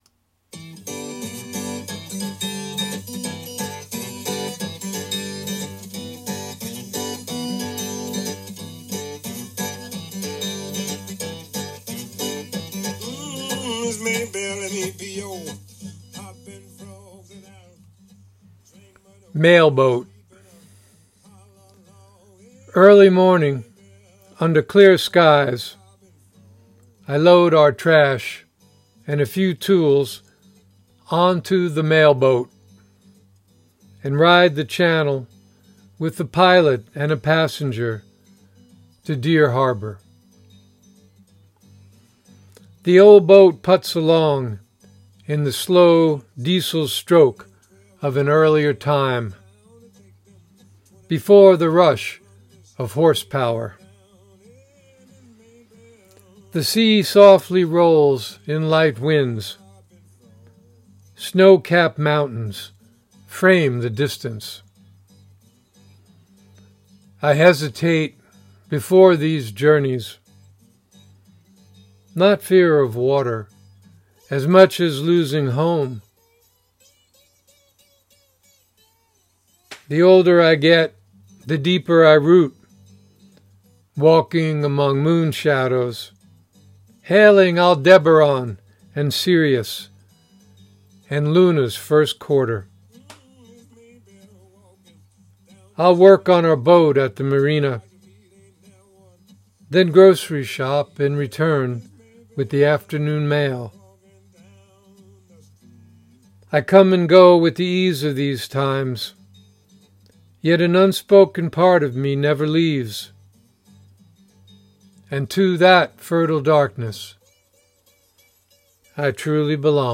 Reading of “Mail Boat” with music by R.L. Burnside